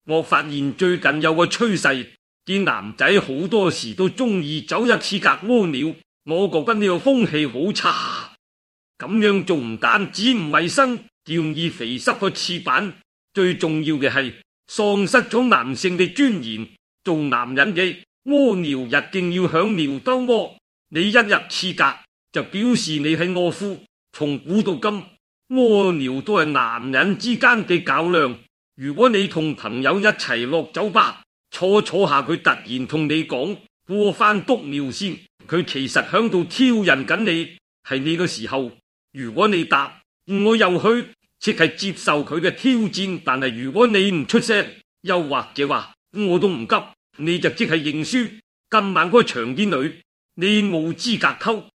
雖然聽到仲有啲口音，可能可以 train 多幾千個 step 試下會唔會好啲，但佢 voice cloning 效果都算唔錯，學到啲說話風格。
tts_result.mp3